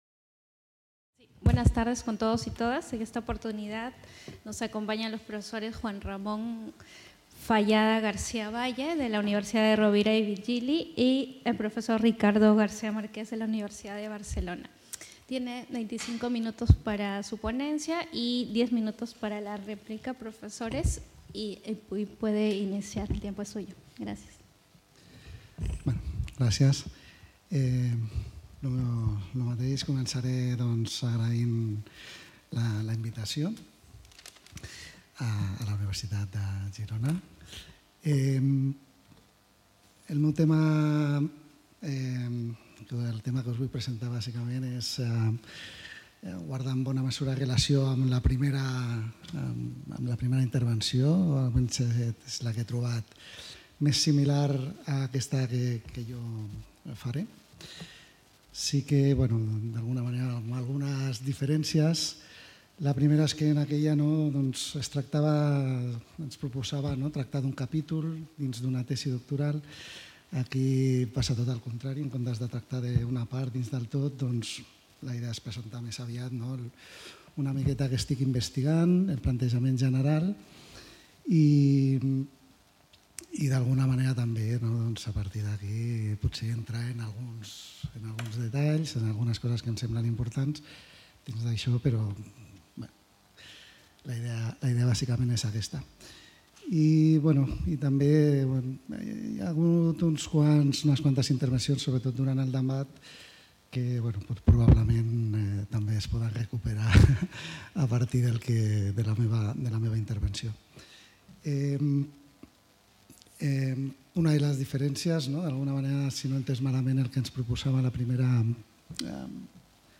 The Chair of Legal Culture of the UdG organizes the II Catalan Interuniversity Seminar on Philosophy of Law.
La Càtedra de Cultura Jurídica de la UdG organitza el II Seminari interuniversitari català de filosofia del dret.